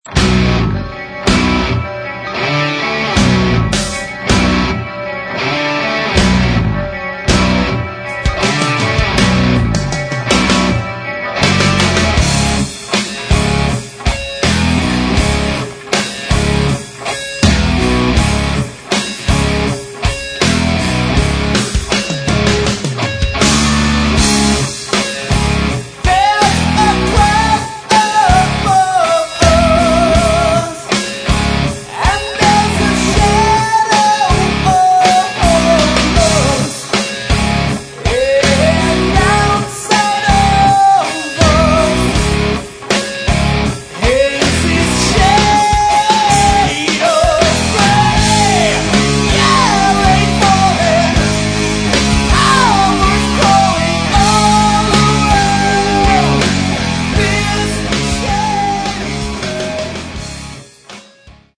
Metal
клавиши, фортепьяно